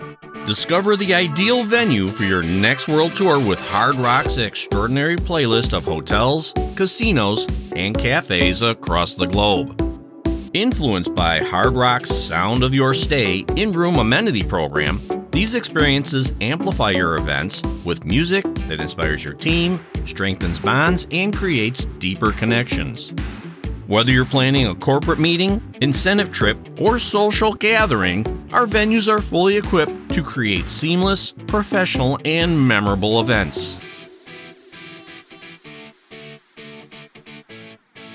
American Male Voice Over Artist
I work out of a broadcast quality home studio with professional recording equipment and a quick turnaround time!